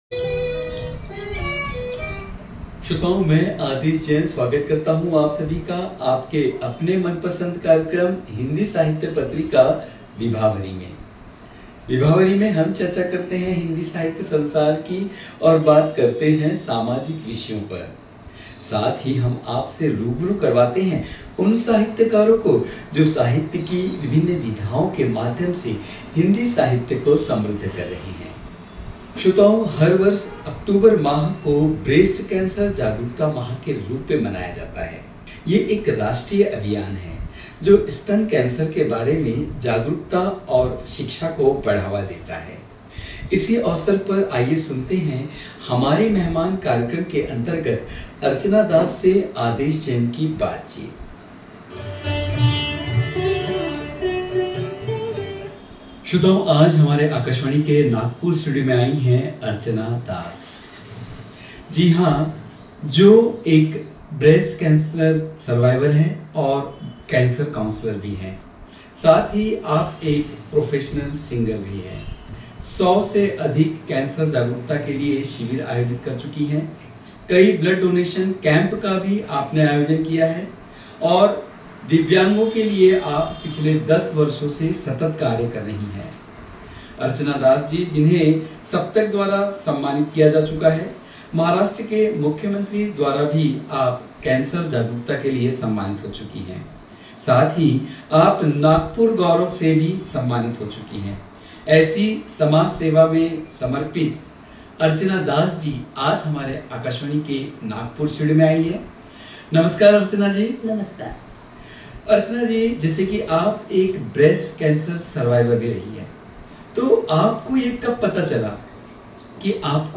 On October 21, 2024, at 11:30 AM, Akashvani Nagpur aired a special episode of Vibhavari in observance of Breast Cancer Awareness Month.